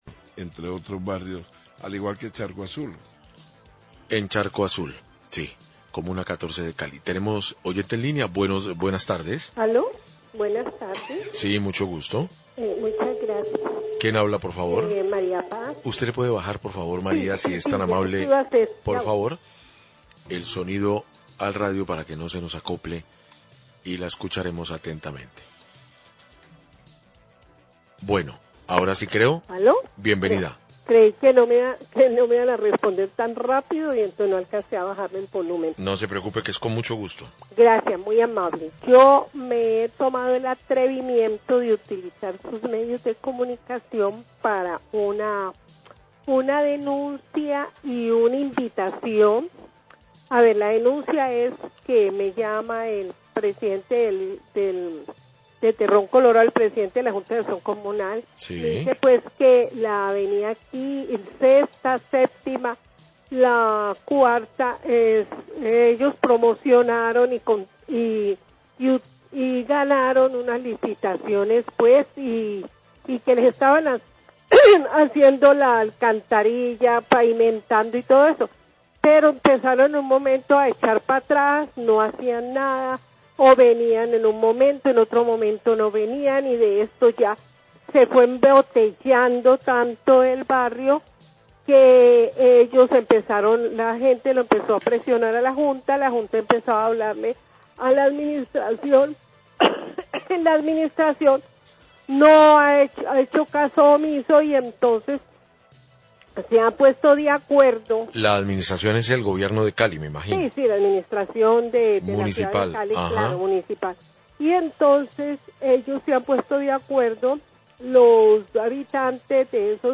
NOTICIAS DE CALIDAD
Oyente manifiesta que la comunidad de Terrón Colorado realizará este jueves un plantón en la vía al mar, como protesta por incumplimiento de obras de alcantarillado y pavimentación de las calles.